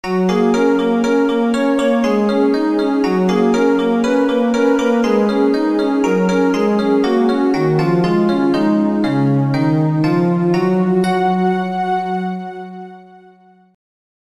＊実録（テープ放送）と音程が違いますがご了承ください。